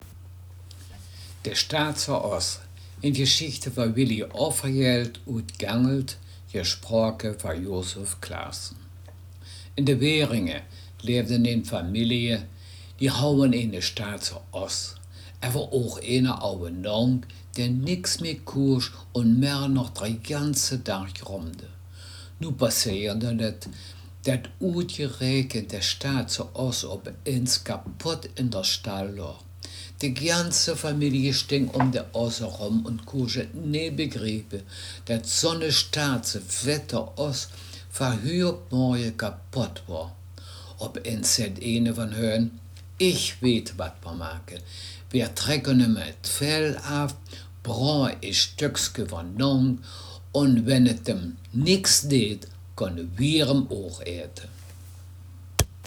Text Mundart
Gangelter-Waldfeuchter-Platt
Geschichte